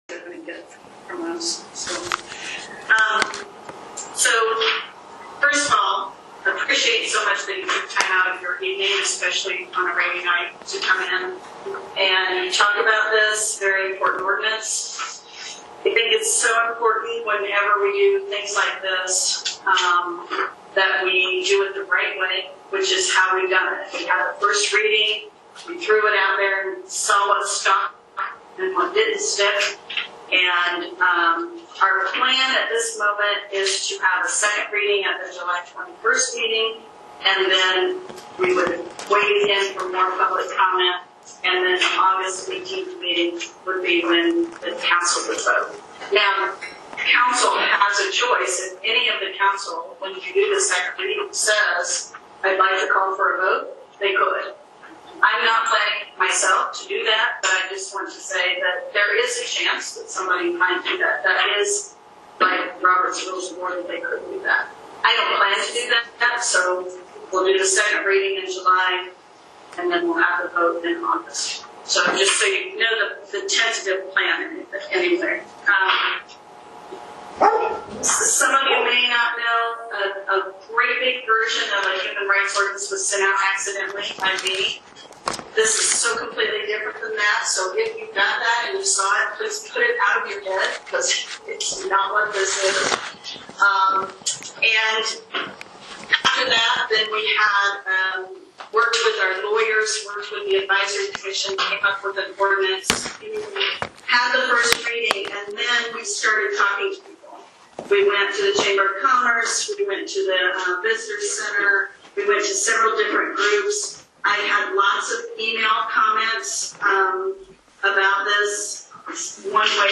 Nashville Town Hall Meeting – Proposed HRC Ordinance July 7, 2022